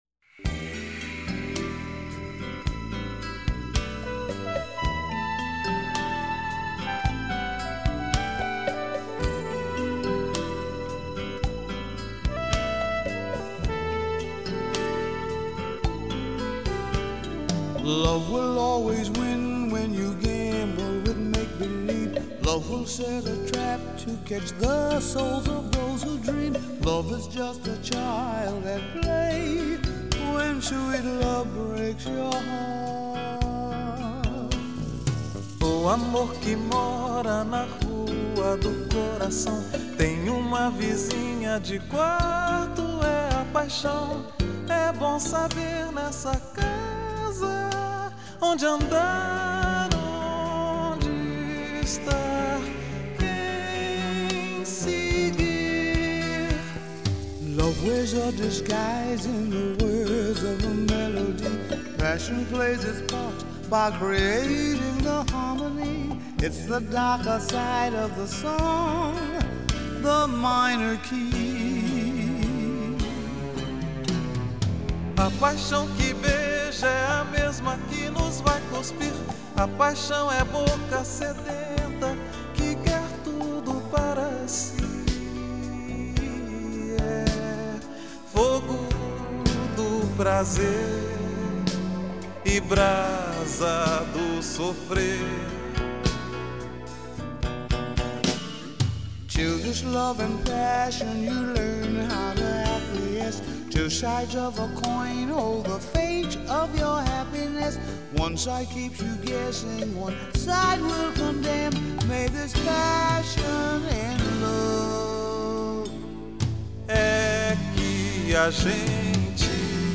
in duet